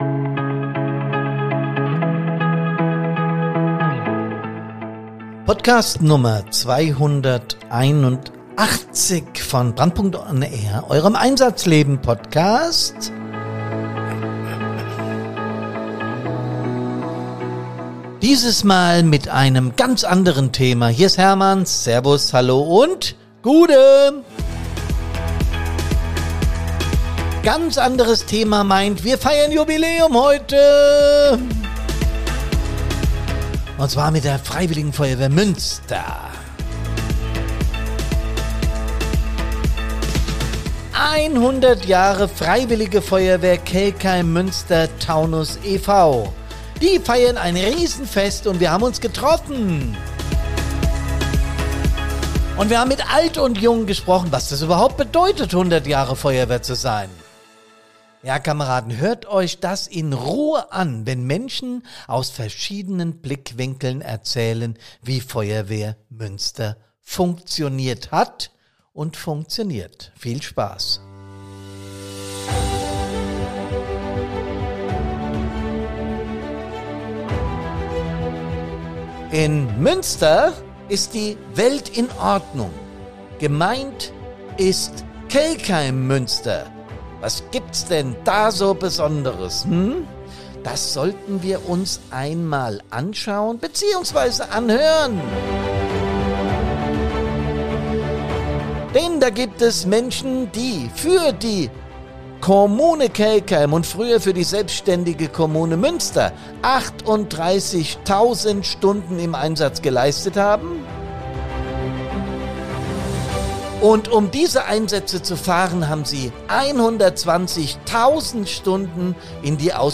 Wir haben die Münsterer interviewt. Rausgekommen ist ein spannender Podcast mit vielen unterschiedlichen Perspektiven auf 100 Jahre Feuerwehr Münster.